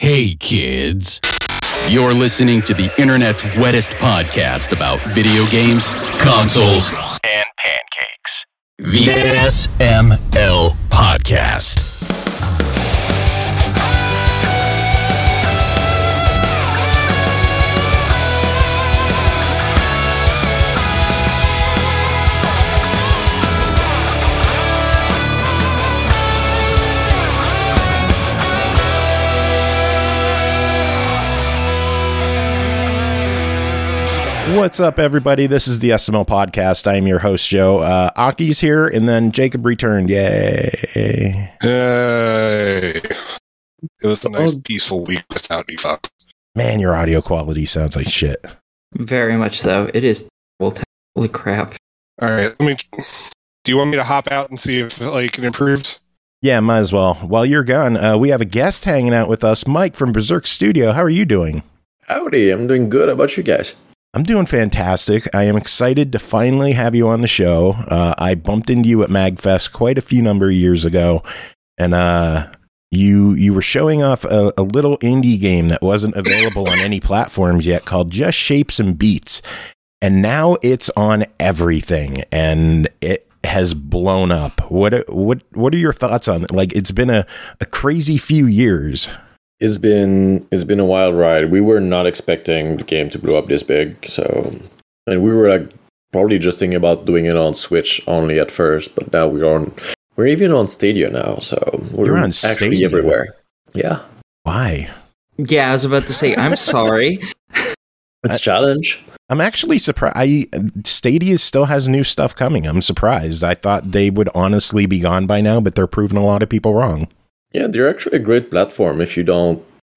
Yay tech issues!
Intro/Berzerk Studio Interview